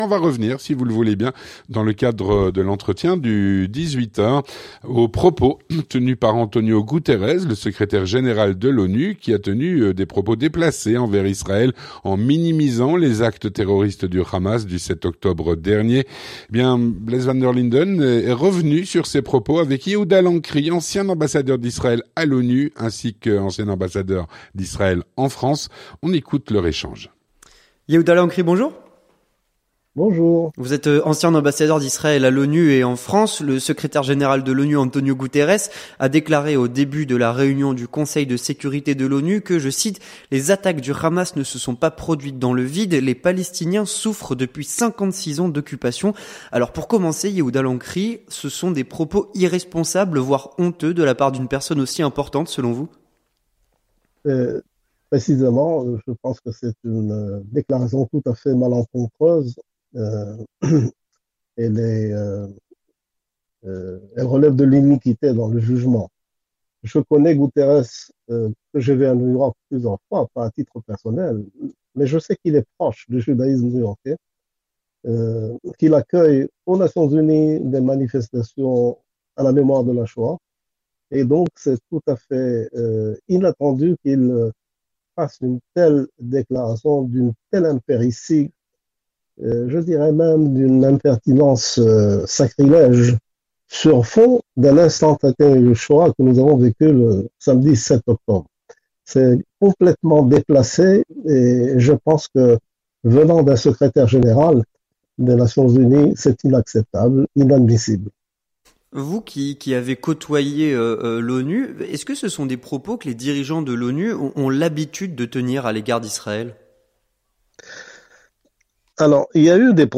L'entretien du 18H - Propos controversés d'Antonio Gutteres à propos du conflit Israël/Hamas. Avec Yehuda Lancry (26/10/2023)
Avec Yehuda Lancry, ancien ambassadeur d'Israël à l'ONU et en France